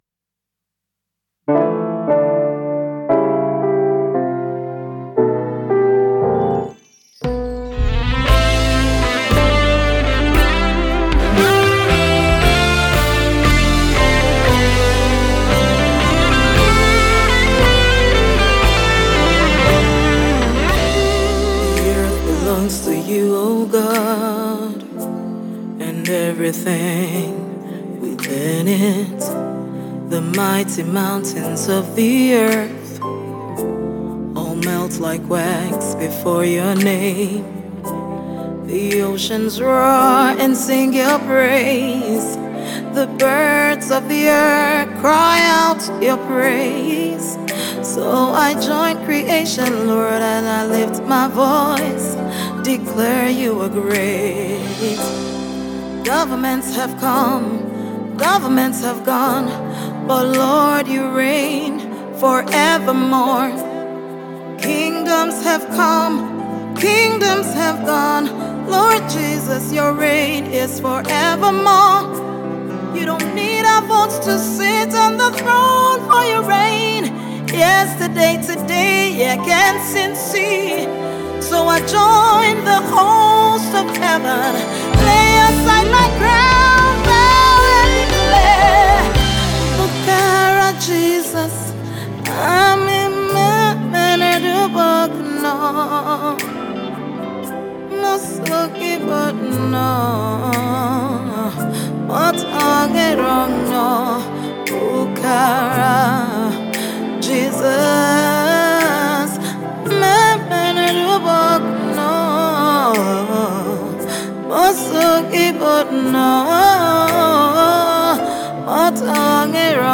Sensational gospel music songstress and exquisite songwriter
worship anthem
Soul-lifting !!!